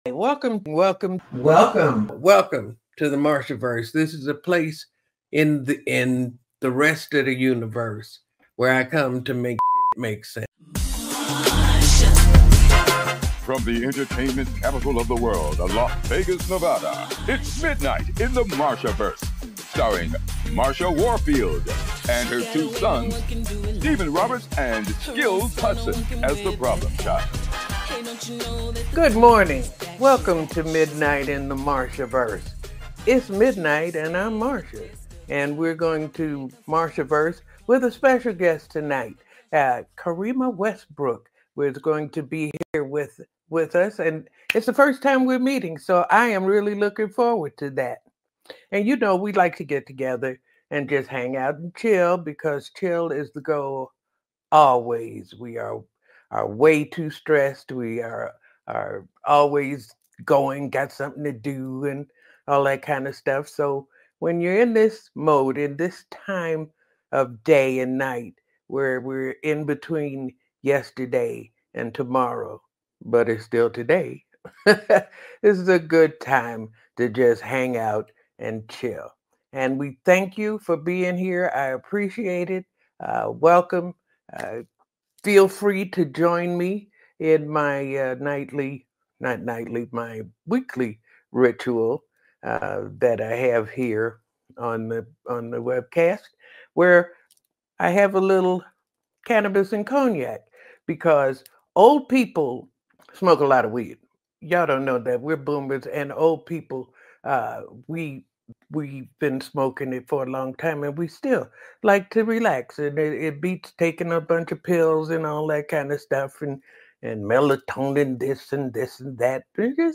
an intimate and insightful conversation 🎙✨ They reflect on growing up in Chicago, the challenges of breaking into Hollywood, and the power of persistence.